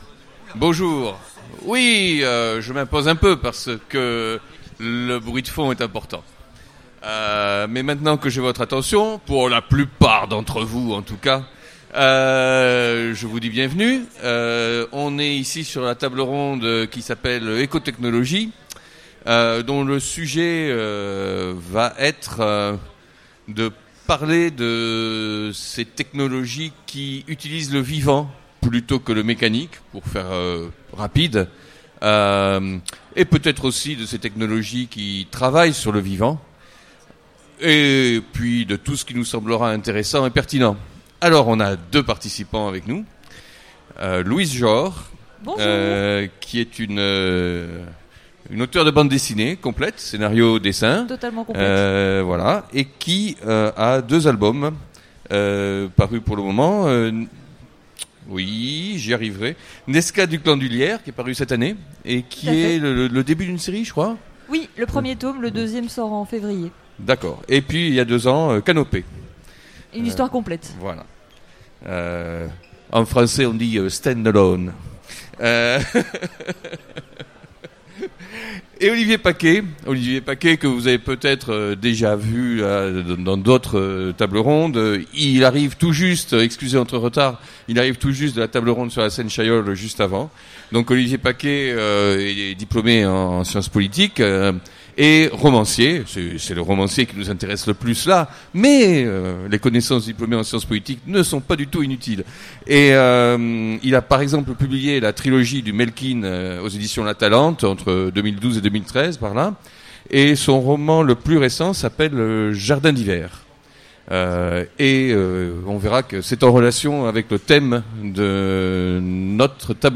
Utopiales 2016 : Conférence Eco-technologie